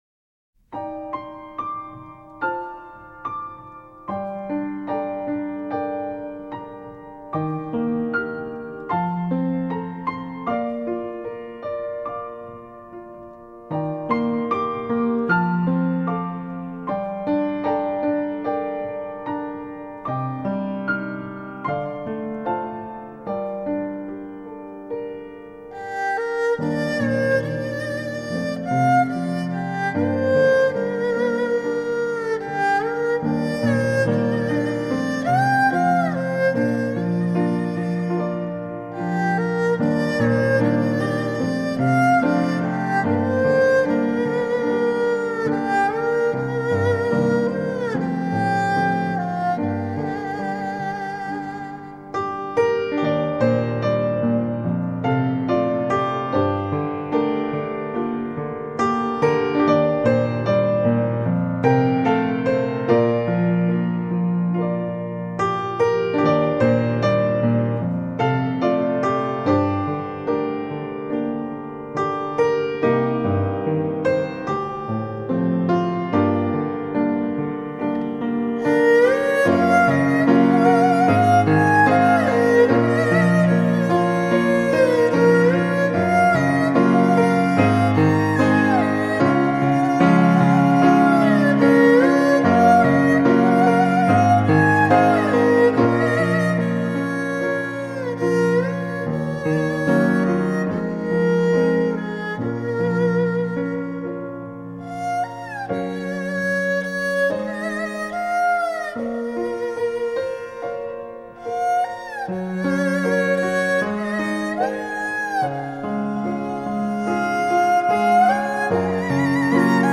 这张专辑最令人耳目一新的是，当中有二胡和钢琴的相互搭配对话，更有另一番味道。
二胡和钢琴的搭配 这样的风格很少见